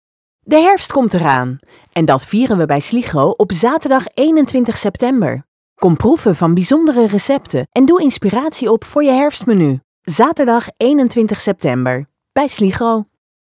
Instore commercials
Tussen de muziek door draait Sligro Radio regelmatig instore commercials.